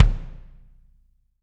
Index of /90_sSampleCDs/Bob Clearmountain Drums I/Partition G/Samples/VOLUME 006